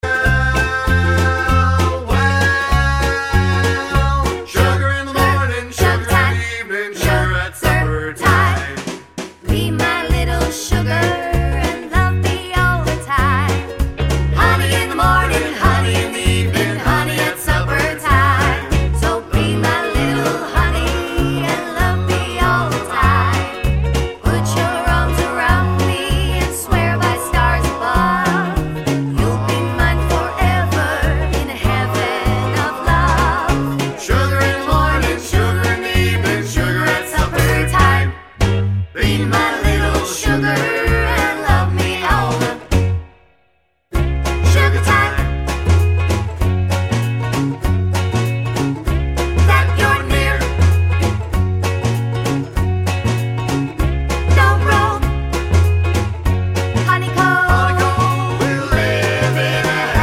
for solo female Oldies (Female) 2:31 Buy £1.50